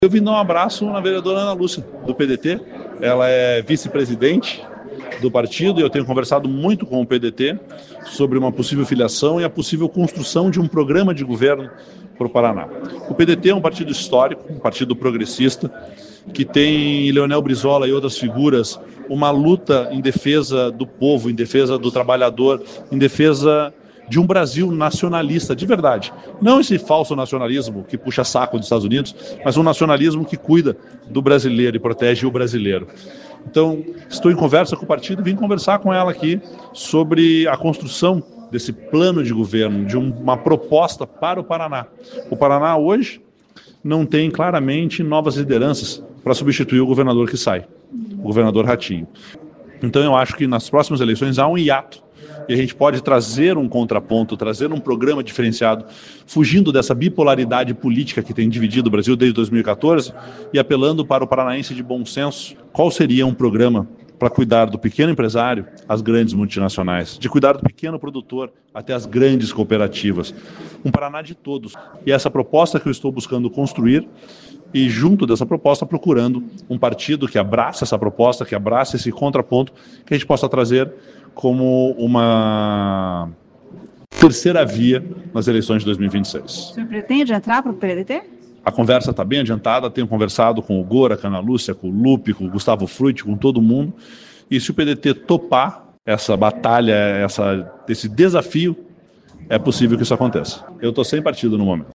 Ouça o que diz o deputado: